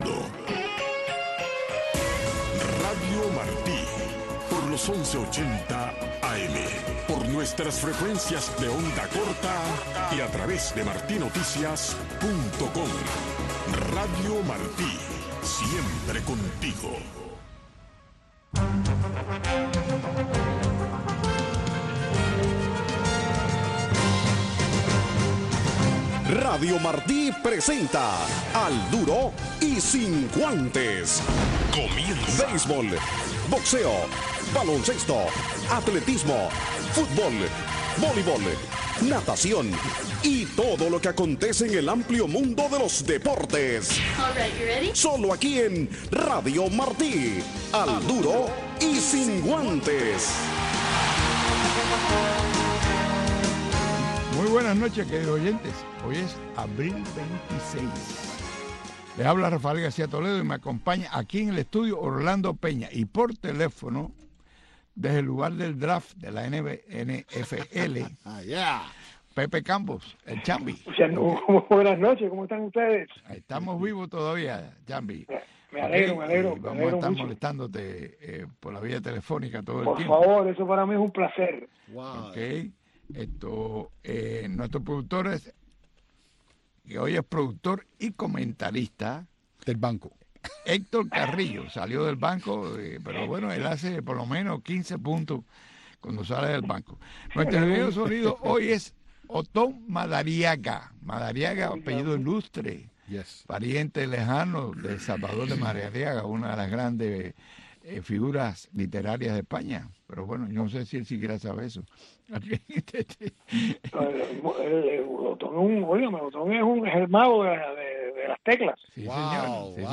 Noticiero Deportivo